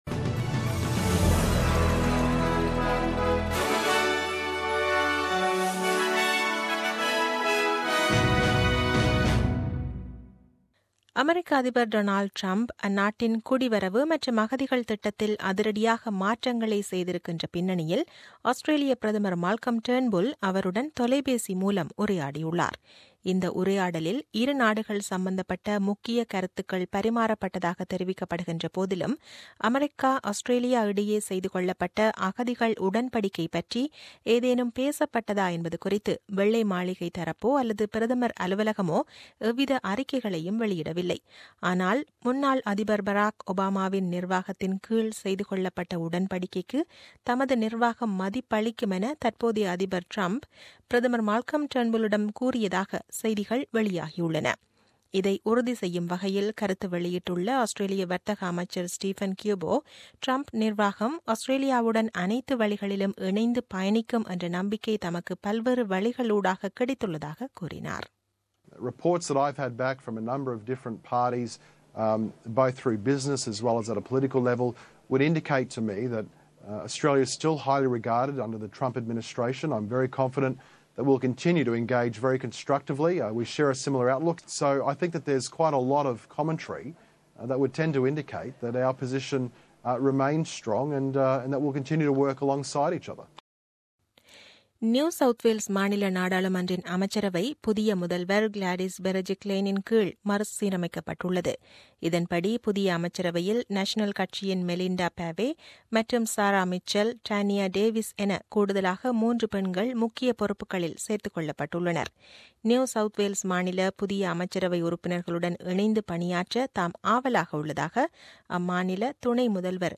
The news bulletin aired on 29 January 2017 at 8pm.